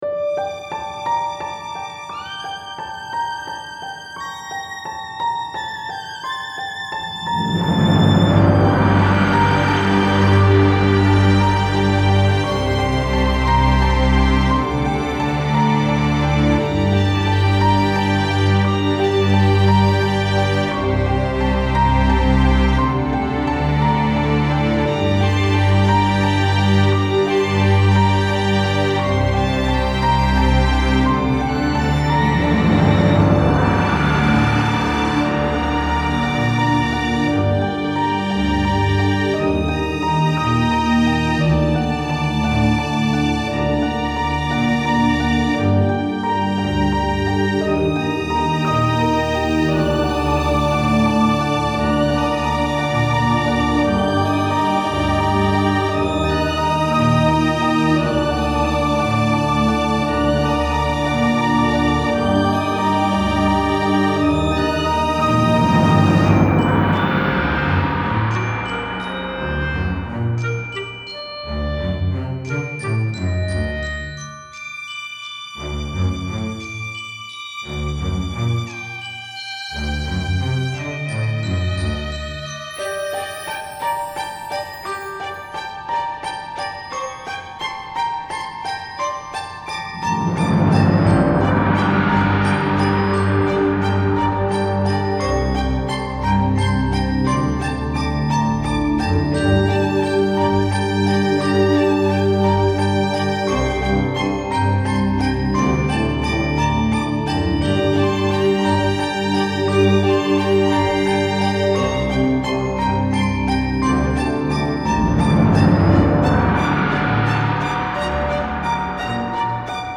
Style Style AmbientOrchestral
Mood Mood CalmingMysterious
BPM BPM 86.5